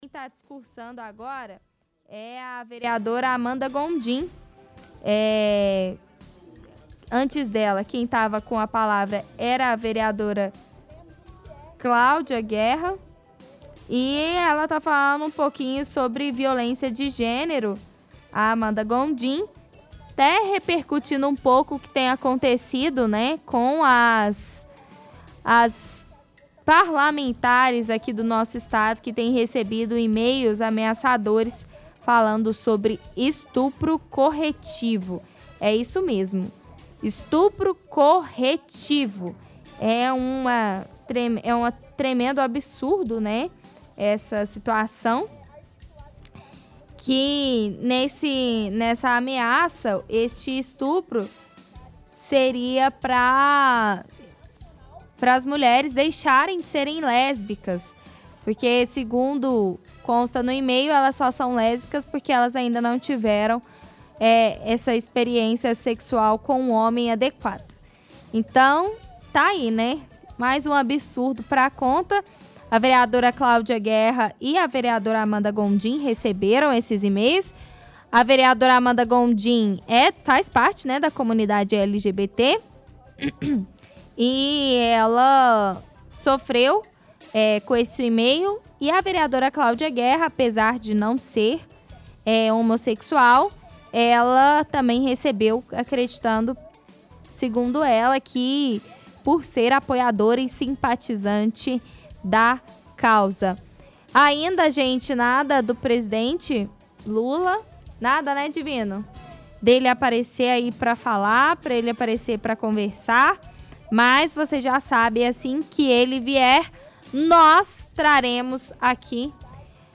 – Quem fala agora sobre violência política contra a mulher é a vereadora Amanda Gondim.